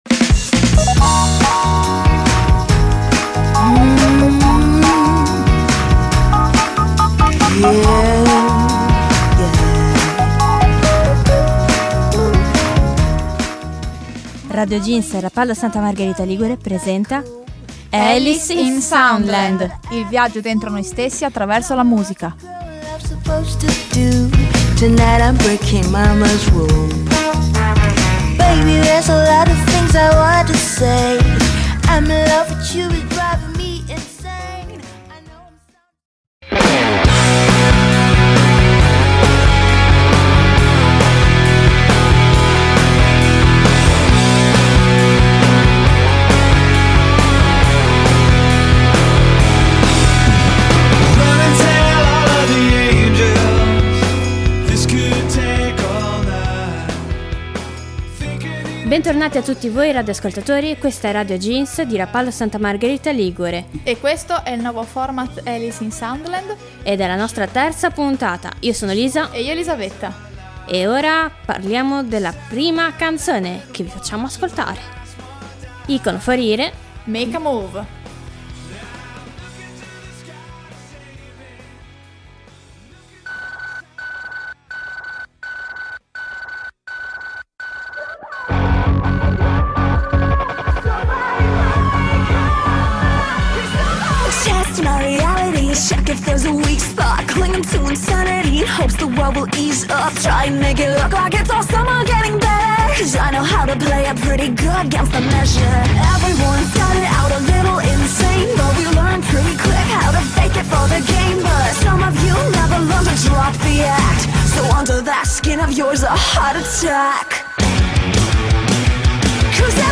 play_circle_filled Alice in Soundland (Puntata 3) Radioweb C.A.G. di Rapallo Terza puntata del format musicale di radio Jeans Rapallo-Santa Margherita Ligure.